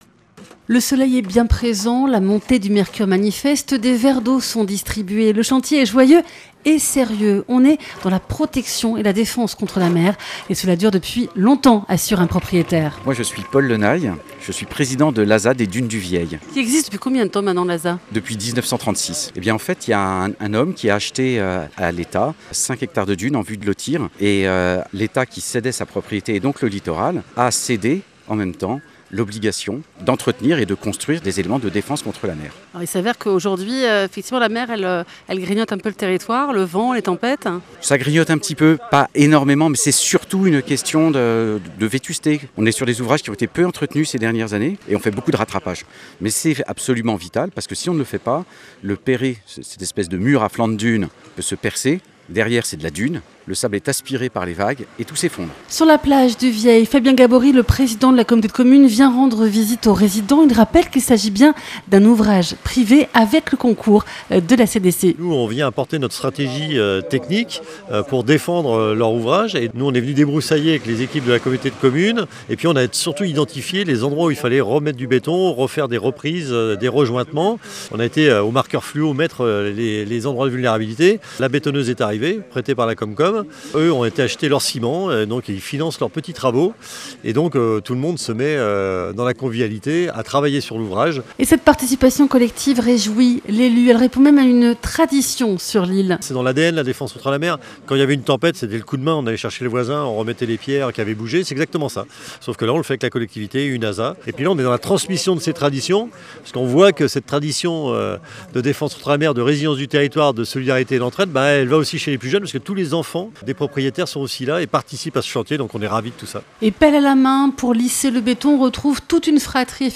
Sur l’île de Noirmoutier, un chantier participatif anime des habitants du Vieil
D’autres s’activent autour d’une bétonneuse et du perré, devant leurs maisons, toutes proches de la plage.